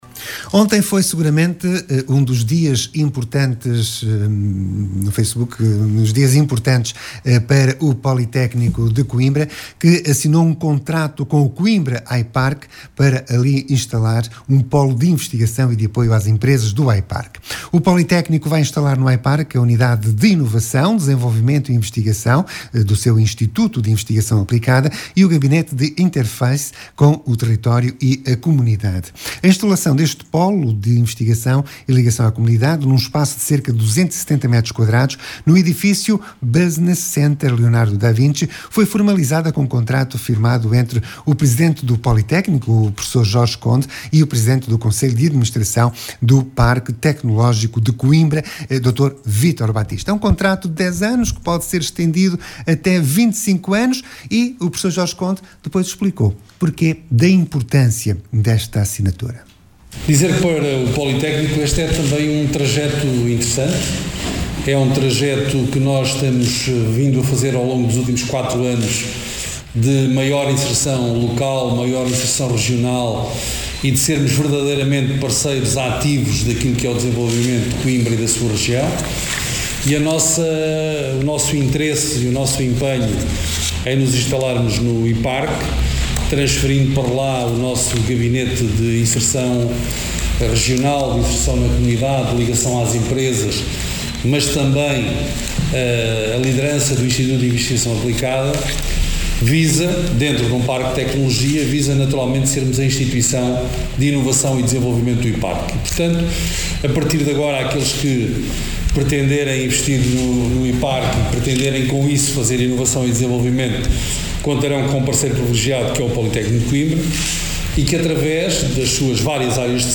Hoje, no Programa da Manhã, o presidente do Instituto Politécnico de Coimbra, Jorge Conde falou do protocolo entre a instituição que lidera e Coimbra iParque.